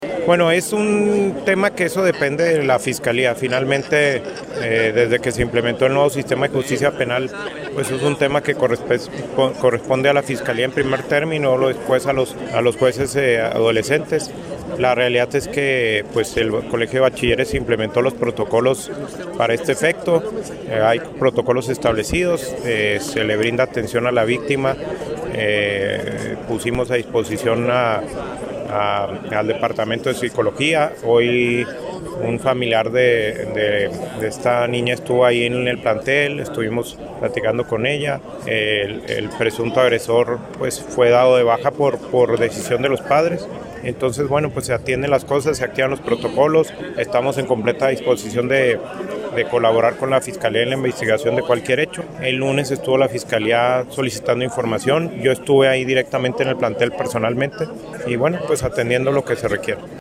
Así lo expuso el Director General del COBACH , Humberto de las Casas Muñoz, quien explicó que el Órgano Interno de Control de la Secretaría de la Función Publica Estatal ya recaba a información para determinar si existieron posibles omisiones que puedan ser constitutivas de algún delito.